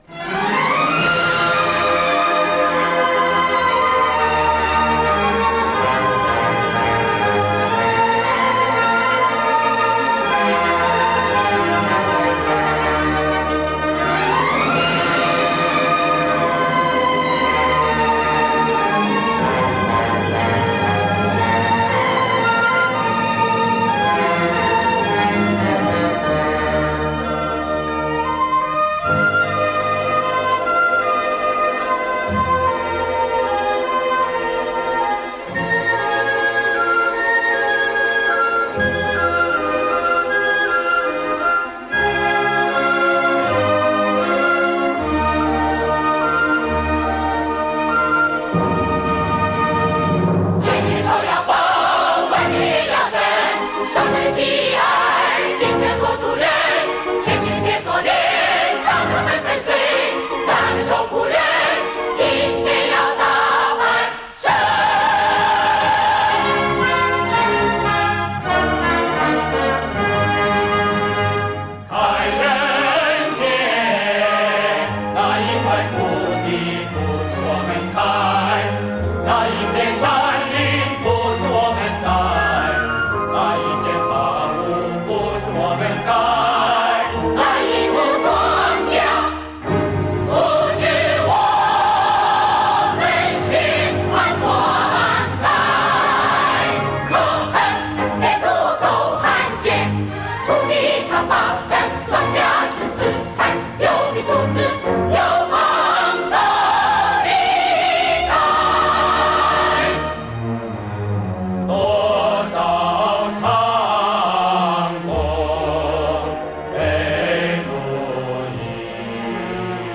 Сцена из оперного балета